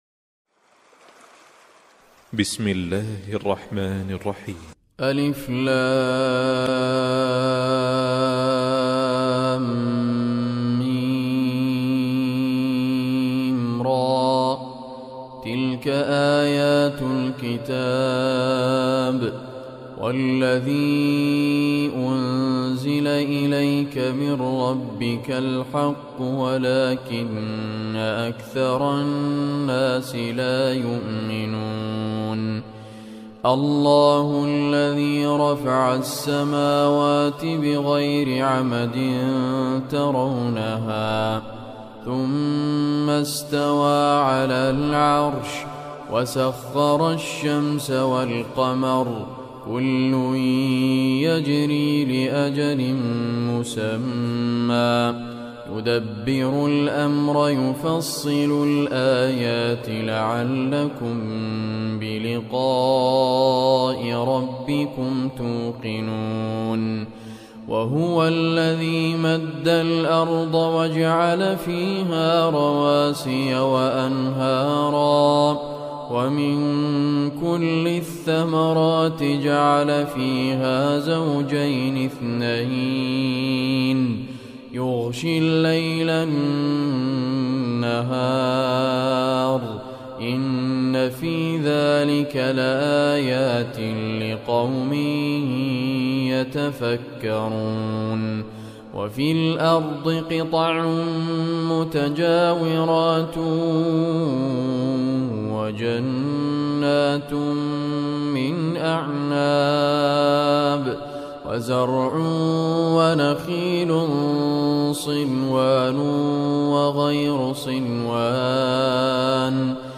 Surah Ar-Raad Recitation by Sheikh Raad Al Kurdi
Surah Ar-Raad is 13 chapter of Holy Quran. Listen or play online mp3 tilawat / recitation of Surah Ar Raad in the voice of Sheikh Raad Muhammad Al Kurdi.